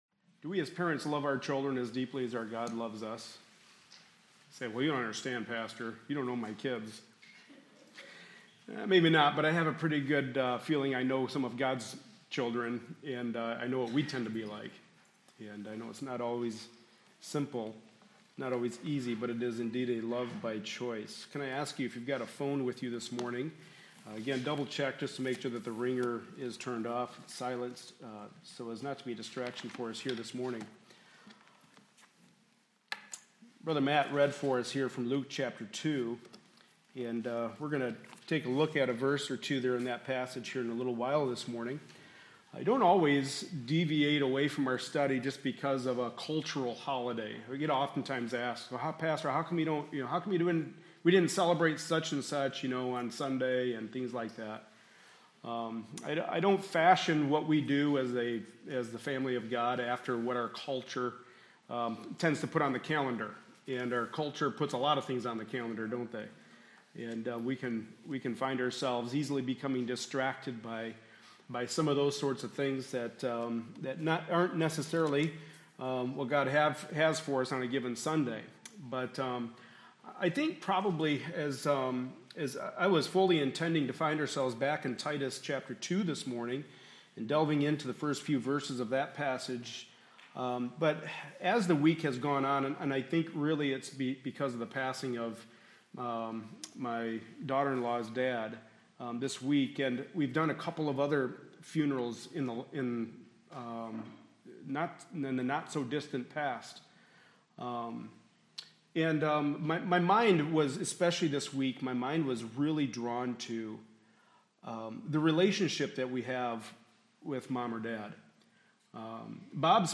Passage: Exodus 20:12 Service Type: Sunday Morning Service Related Topics